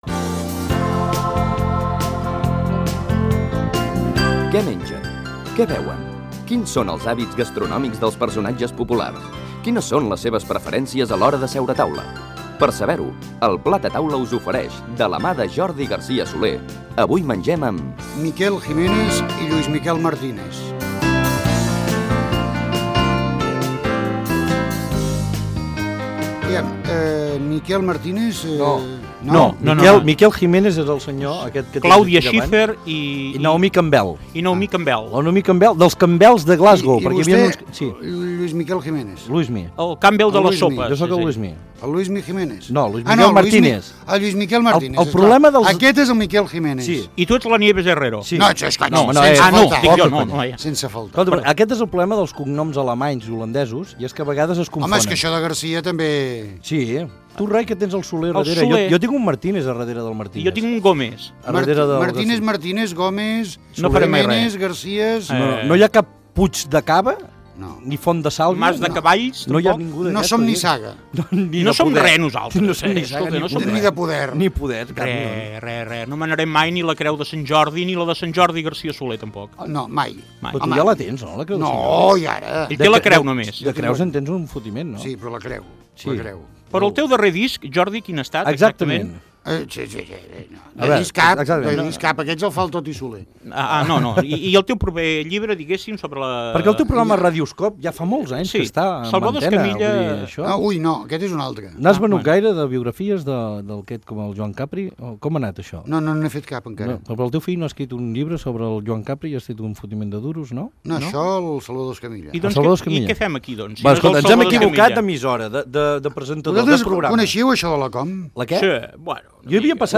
Careta del programa i fragment d'una conversa amb els periodistes i humoristes
Entreteniment